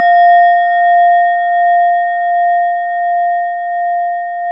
Index of /90_sSampleCDs/E-MU Formula 4000 Series Vol. 4 – Earth Tones/Default Folder/Japanese Bowls